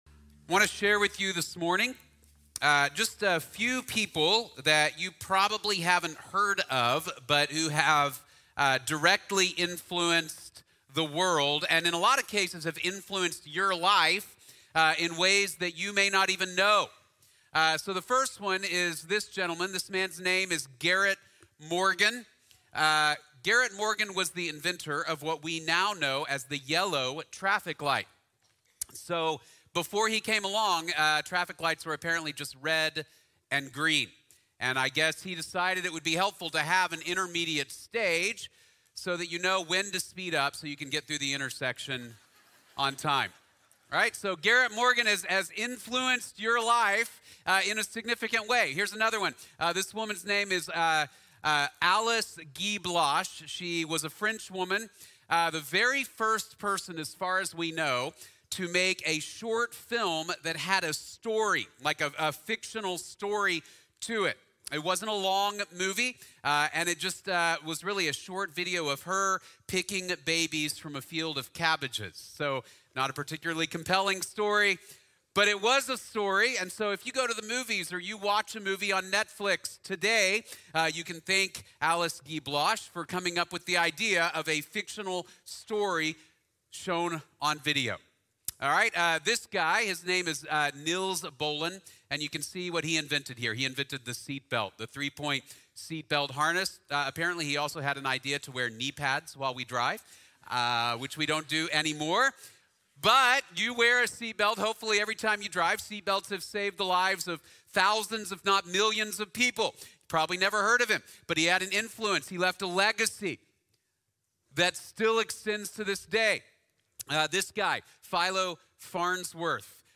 Leaving a Legacy | Sermon | Grace Bible Church